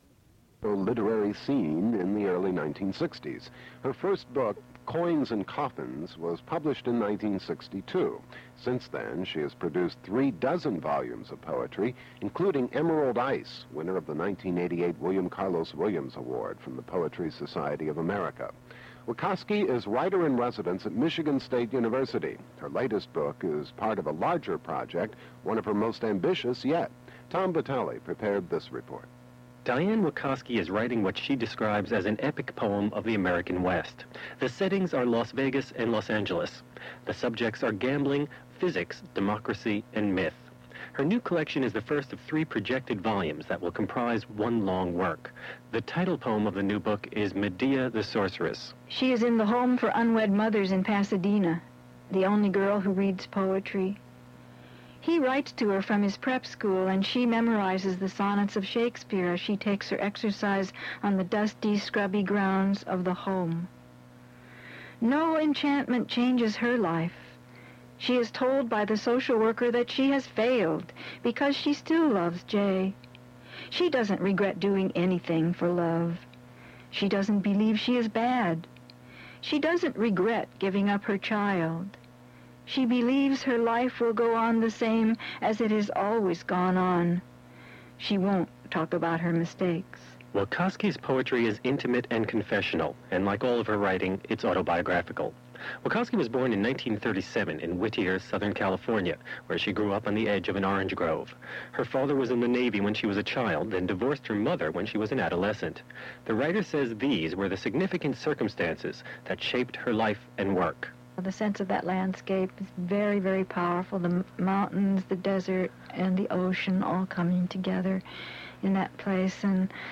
Poetry reading featuring Diane Wakoski
• Diane Wakoski reading her poetry at Duff's Restaurant.
• mp3 edited access file was created from unedited access file which was sourced from preservation WAV file that was generated from original audio cassette.
• A news report tape recording from NPR